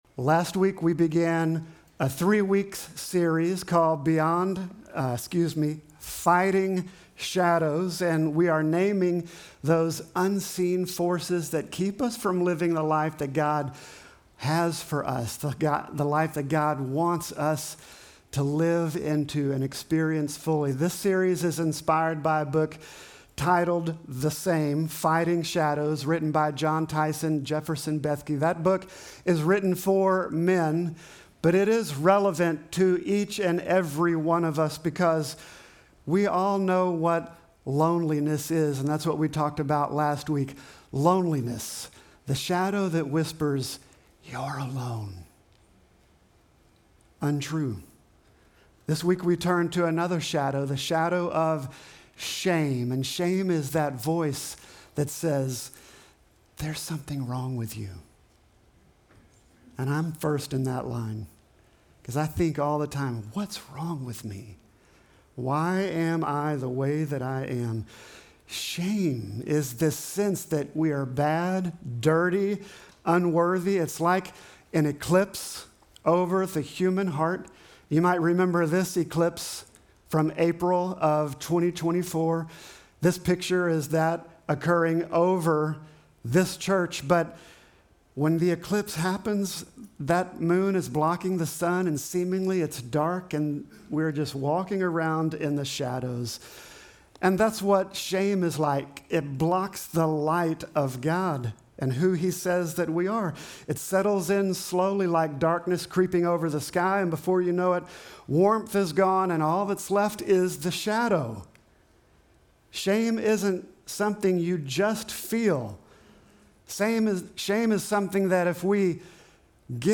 Sermon text: Genesis 2:25